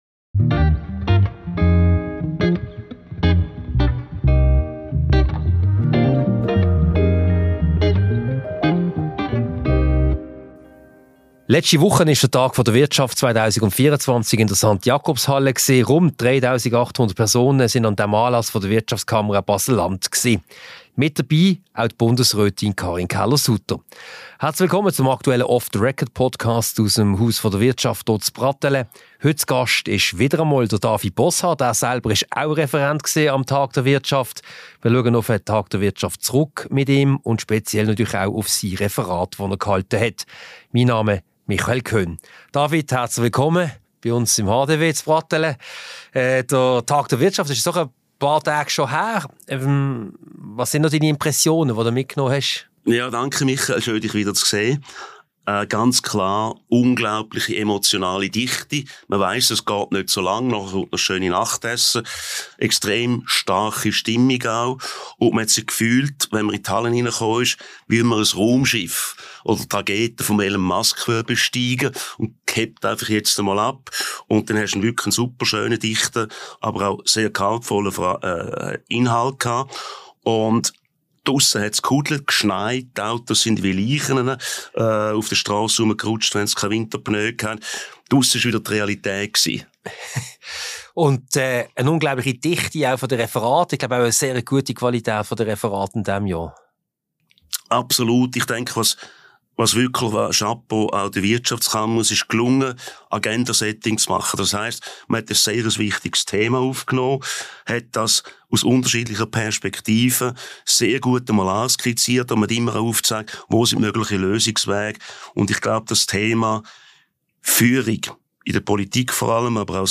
Diese Podcast-Ausgabe wurde im Multimedia-Studio der IWF AG im Haus der Wirtschaft HDW aufgezeichnet.